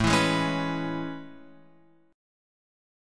A Major 9th